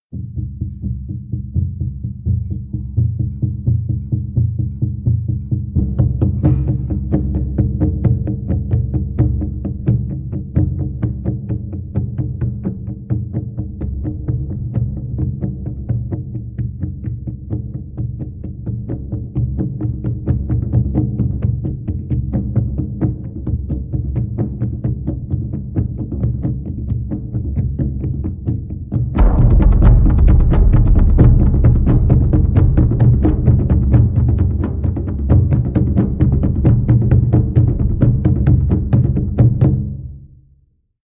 Jumanji 1995 - Drum Sound Effect
Jumanji_1995_-_Drum_Sound_Effect.mp3